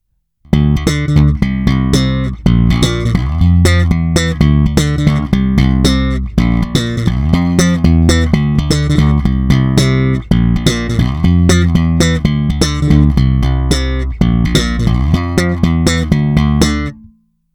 Není-li uvedeno jinak, následující nahrávky jsou provedeny rovnou do zvukovky, jen normalizovány a dále ponechány bez jakéhokoli postprocesingu.
Slap na oba snímače
Slap s EQ skoro naplno